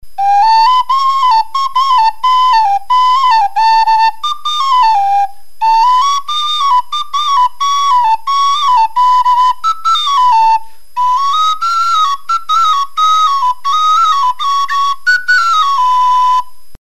URM Sonos de Sardigna: nuovi strumenti - Pipaiolu modernu
PipaioluModernu.mp3